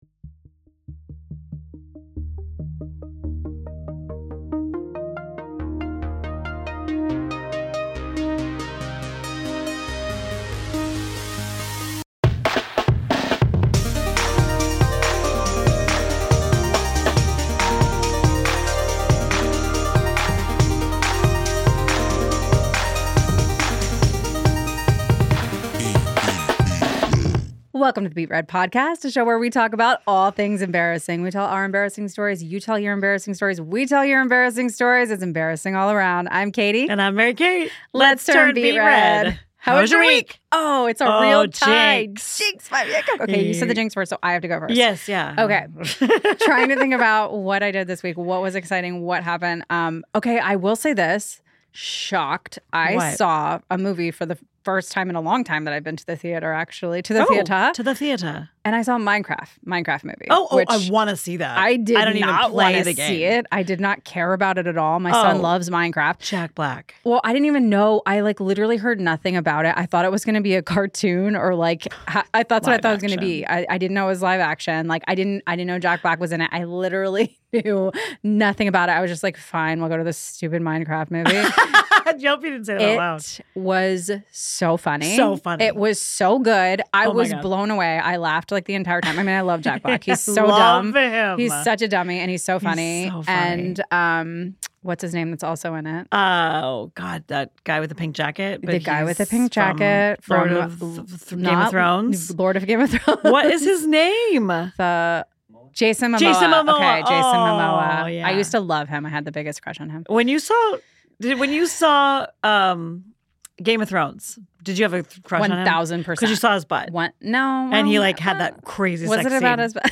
PROJKT studios in Monterey Park, CA.